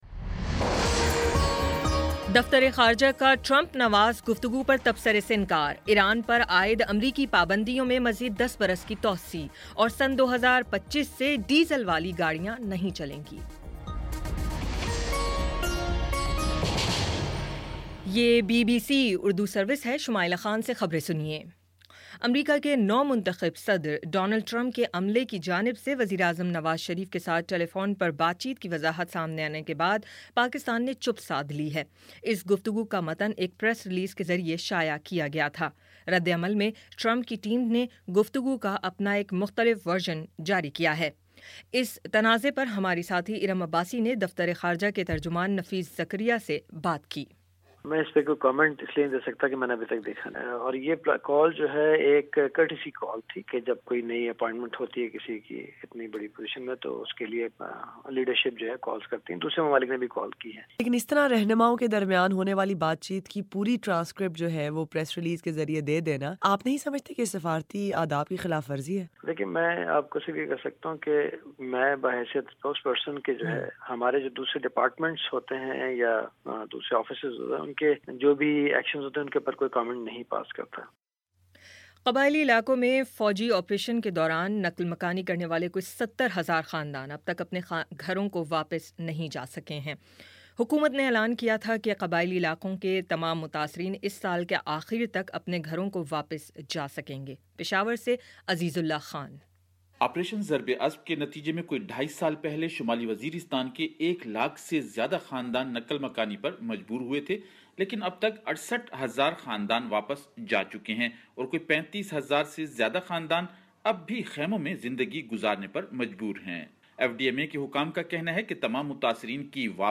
دسمبر 02 : شام پانچ بجے کا نیوز بُلیٹن